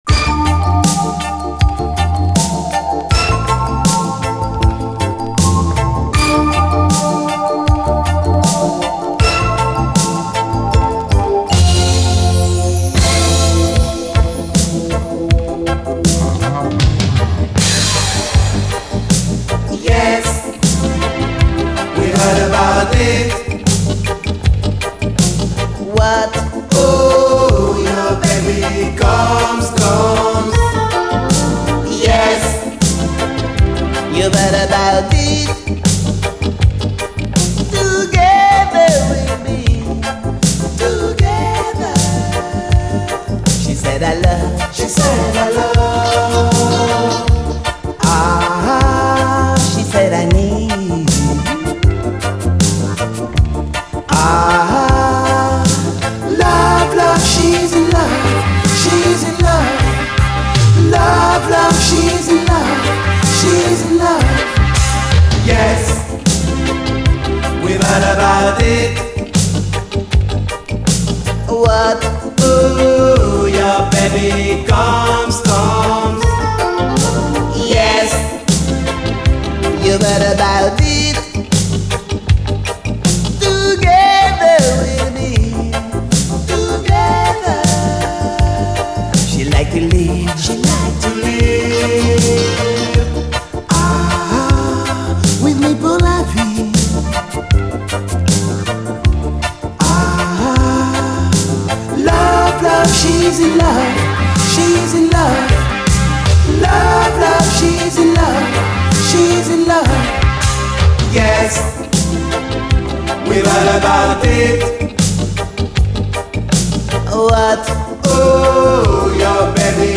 REGGAE, 7INCH
シンセが浮遊する、フランス産80’Sバレアリック・メロウ・レゲエ45！
煮え切らないやるせない歌メロも不思議な浮遊感！